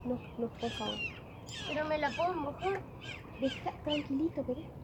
Chimango (Daptrius chimango)
Nombre en inglés: Chimango Caracara
Localización detallada: Cascada de Los Alerces, Parque Nacional Nahuel Huapi
Condición: Silvestre
Certeza: Observada, Vocalización Grabada
Chimango_1.mp3